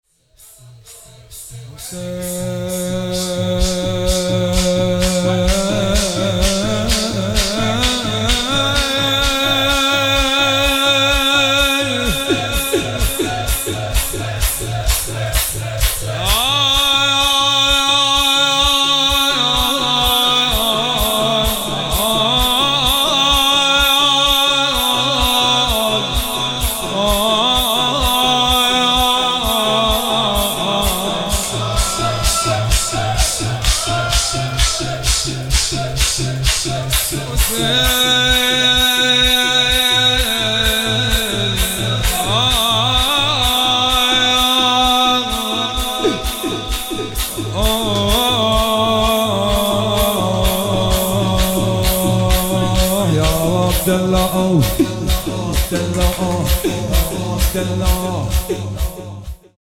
روضه العباس تهران